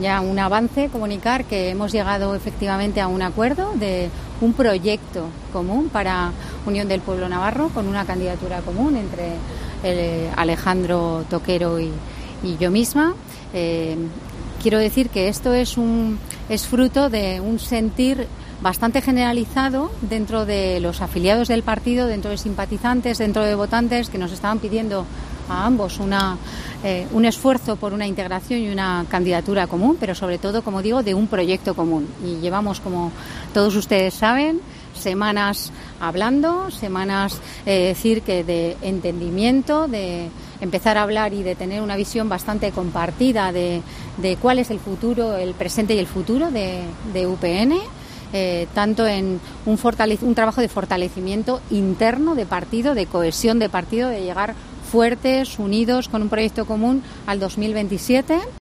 Cristina Ibarrola explica el proyecto de UPN junto a Alejandro Toquero